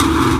VEC3 Percussion 029.wav